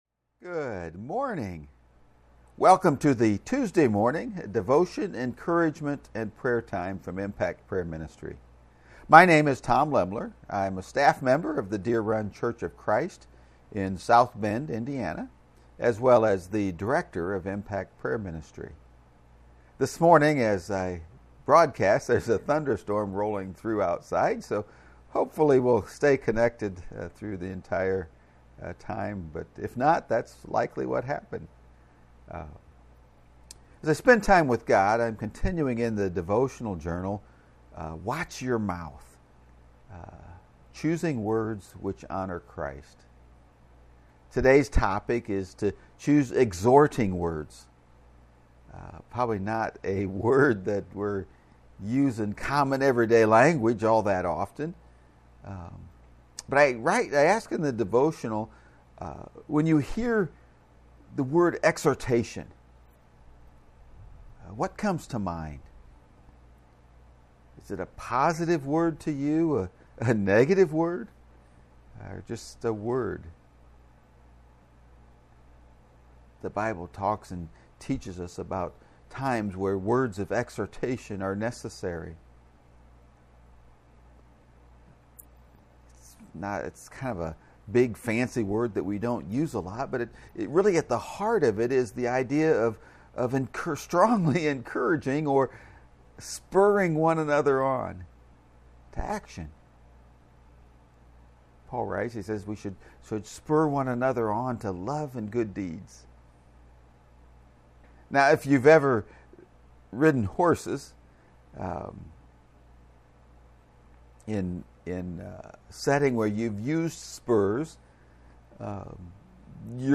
You can find the live video feeds of these encouragement and prayer times on Impact Prayer Ministry’s Facebook page and YouTube channel.